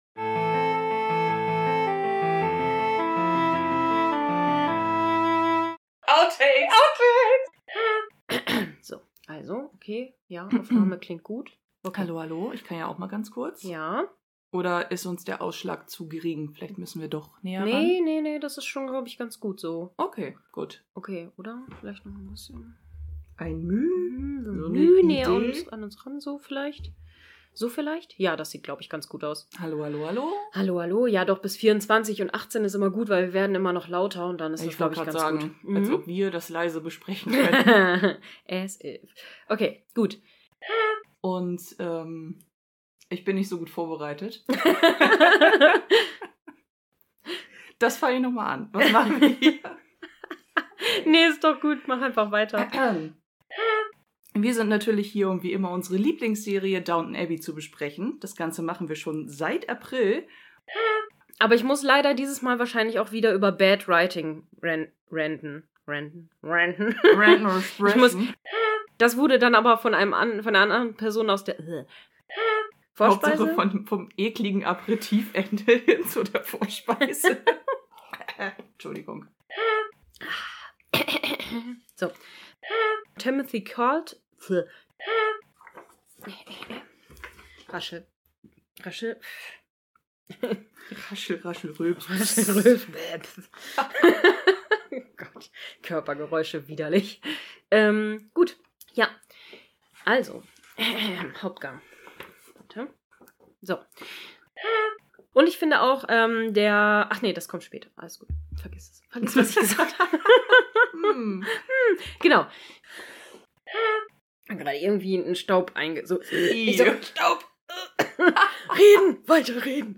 Und wieder ganz viel Quatsch und Katzengestöre bei der Aufnahme.